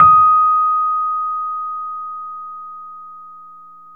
RHODES-EB5.wav